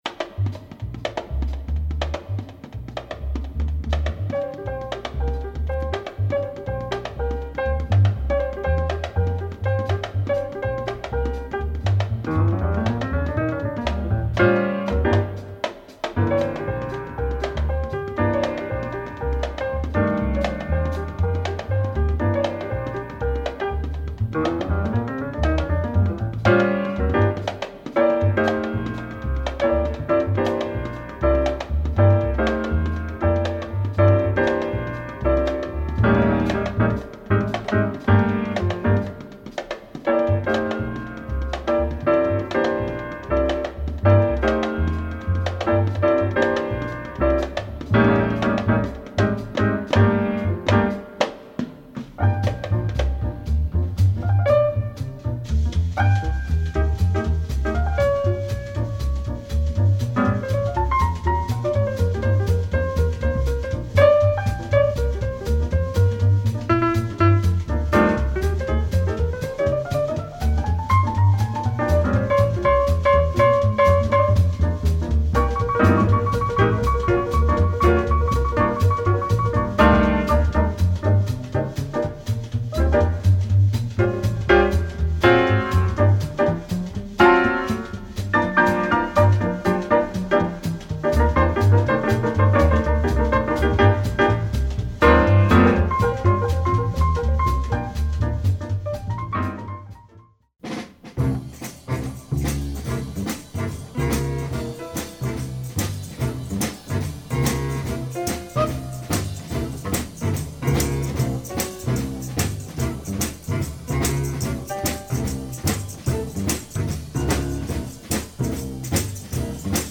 Superb spiritual jazz session !